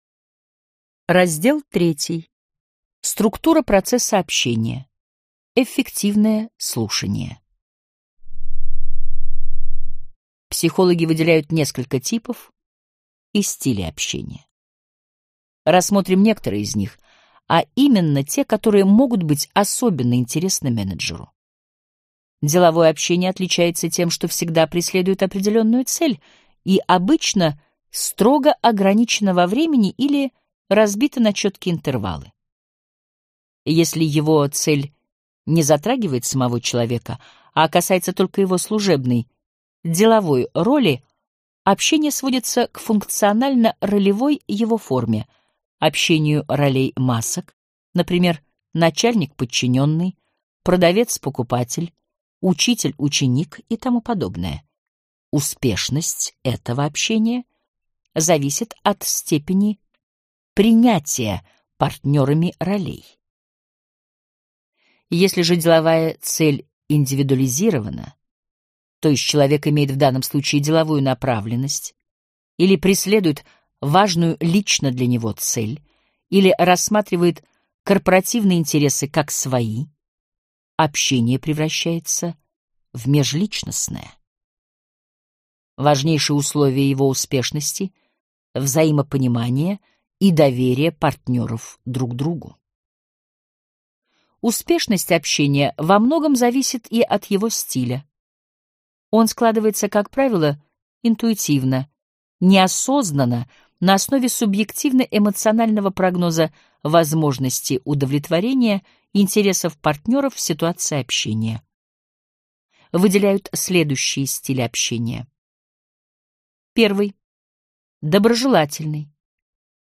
Aудиокнига Психология управления